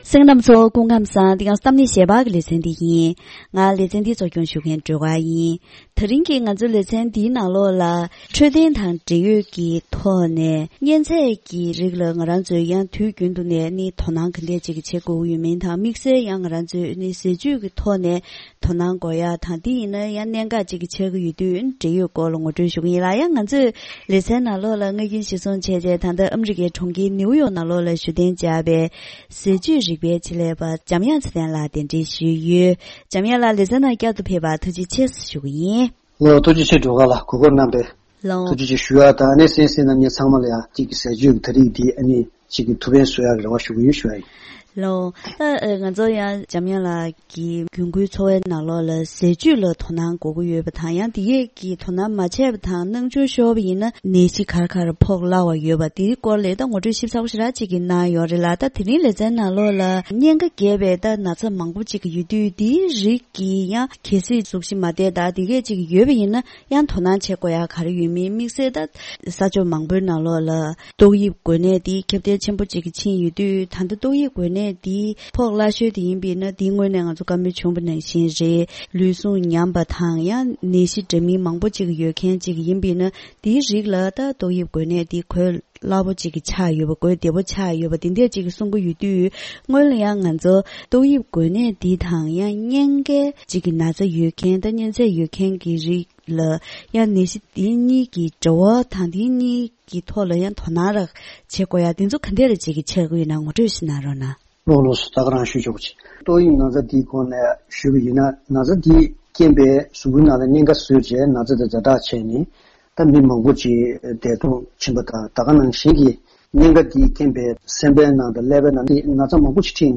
གཉན་ཚད་ལས་བྱུང་པའི་ནད་རིགས་ཁག་ལ་སྔོན་འགོག་དང་ཉིན་རེའི་འཚོ་བའི་ནང་ཟས་རིགས་བེད་སྤྱོད་བྱེད་ཕྱོགས་སོགས་ཀྱི་ཐད་ཆེད་ལས་མི་སྣར་བཀའ་འདྲི་ཞུས་པ།